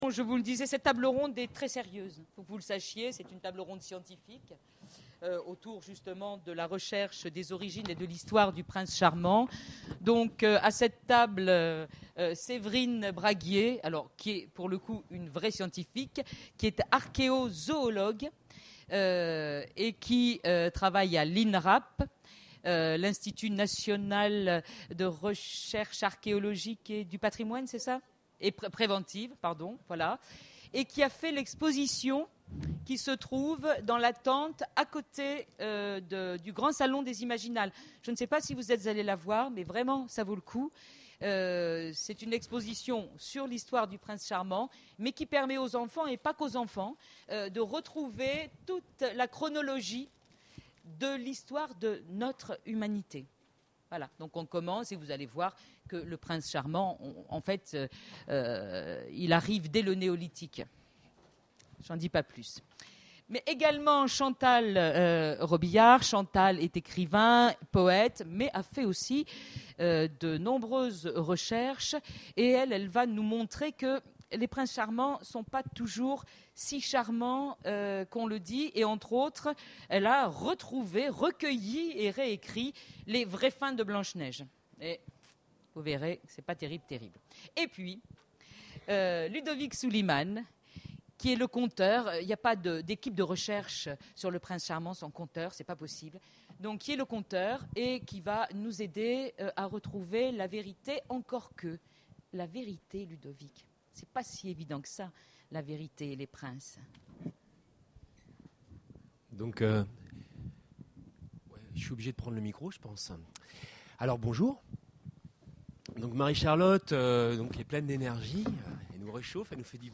Imaginales 2013 : Conférence Le prince charmant...